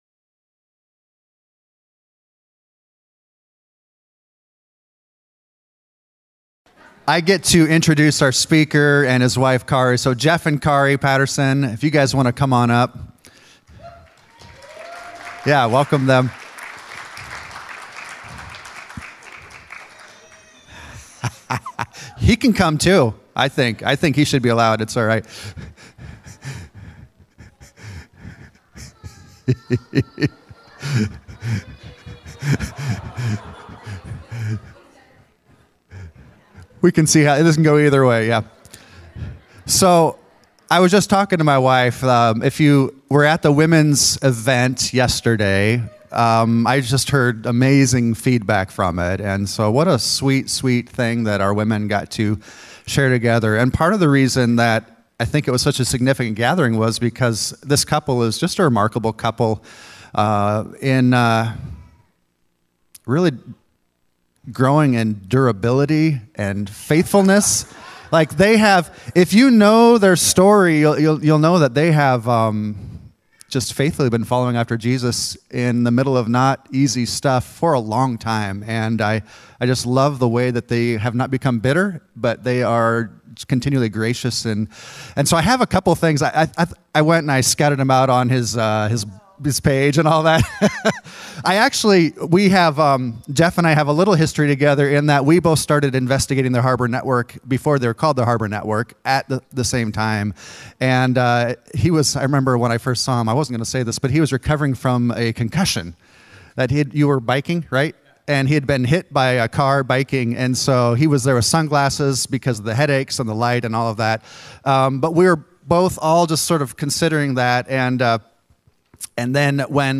Harbor Network Combo Service feat. Connection Point Church | The Vine Community | Renew Church